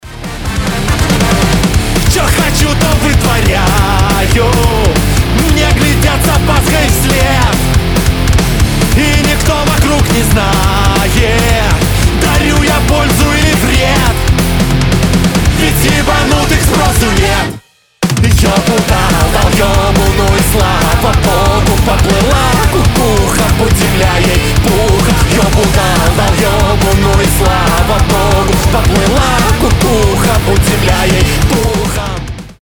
• Качество: 320, Stereo
громкие
быстрые
ска-панк